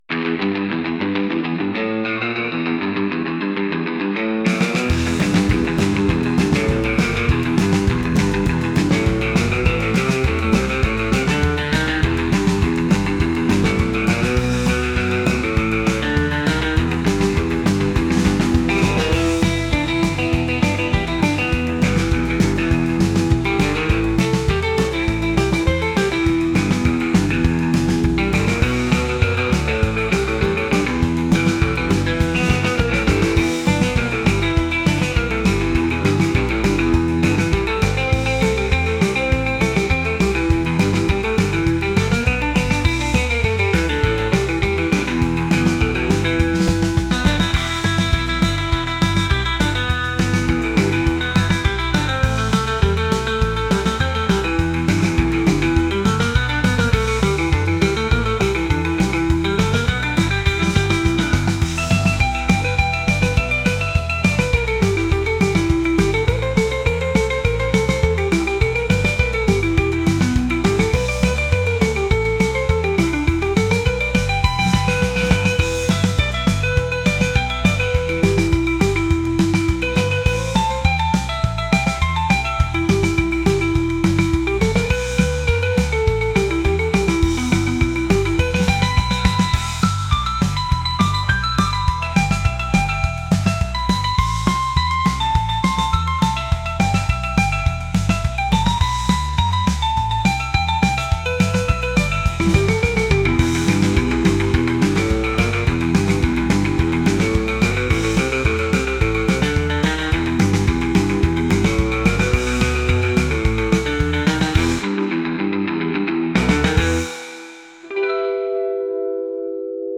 (surf rock)
[Instrumental]